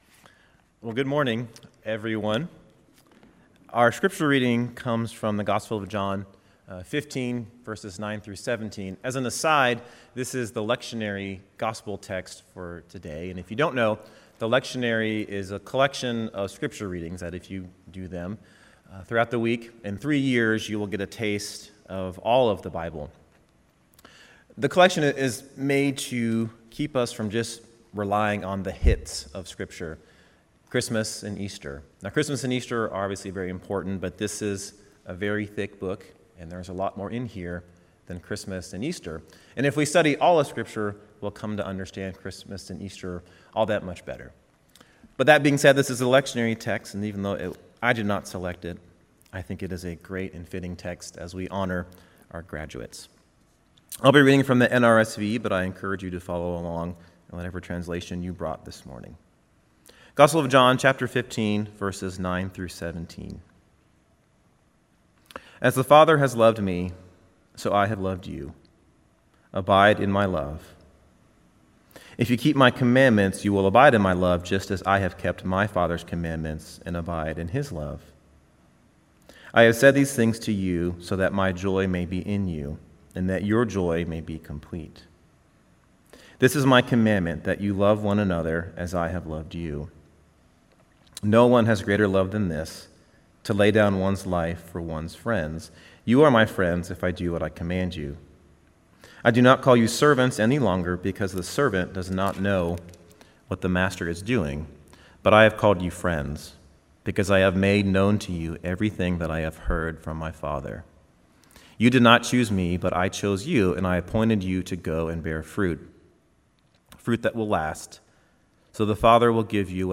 Service Type: Guest Preacher